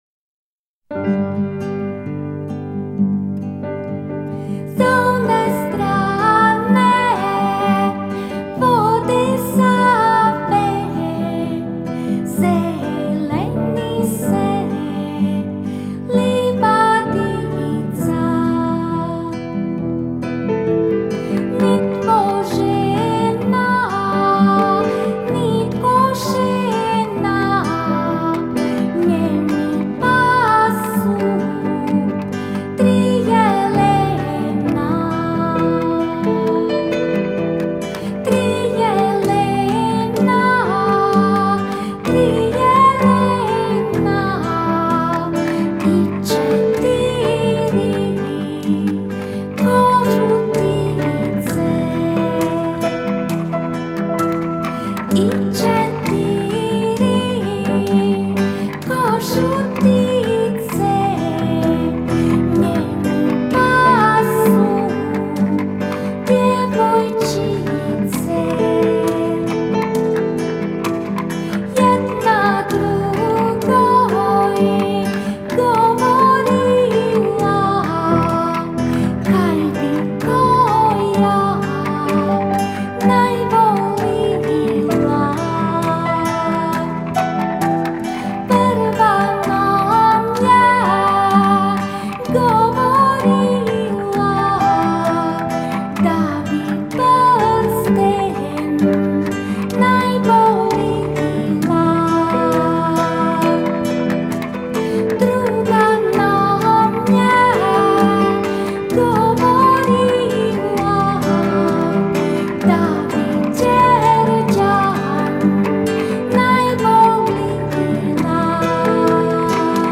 glas i gitara
glasovir i udaraljke
cimbale